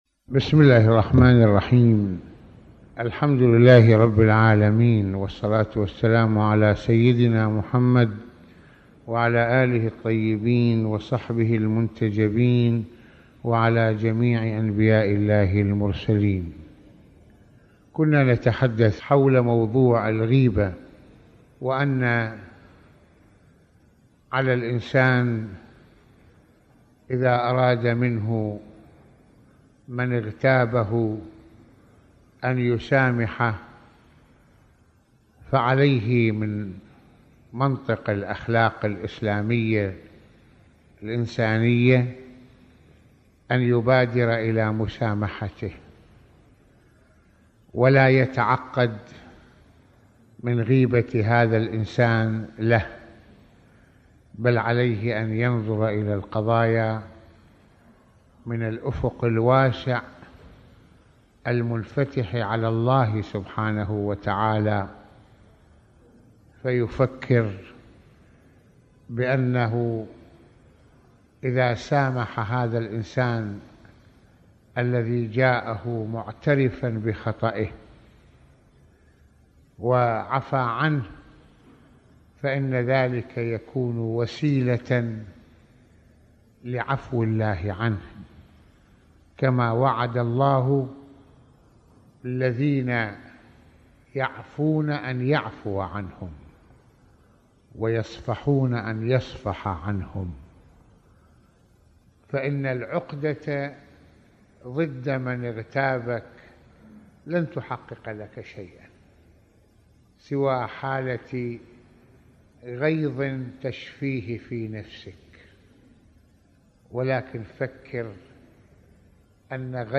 حديث السحر: المسامحة | محاضرات رمضانية
- يتحدث العلامة المرجع السيد محمد حسين فضل الله في هذه المحاضرة الرمضانية عن ضرورة المسامحة والتسامح وفلسفته في الاسلام ويشرح فقرات من دعاء الإمام زين العابدين (ع) في طلب العفو والرحمة حيث يتناول مسائل الشهوة والعنف والرفق ، ويدعو سماحته الى حل المشاكل